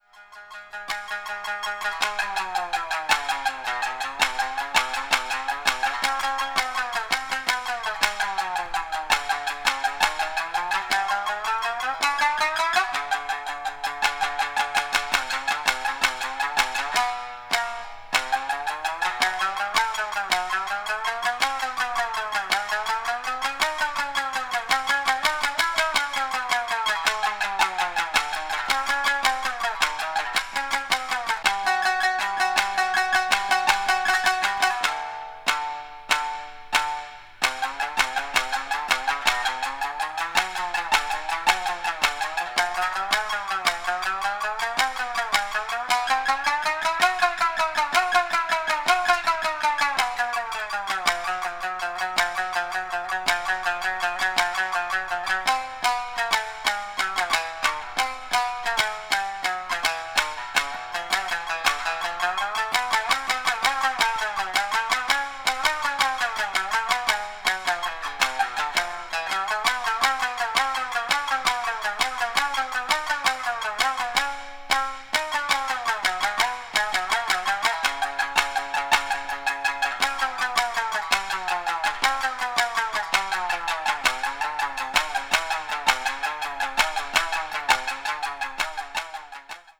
三絃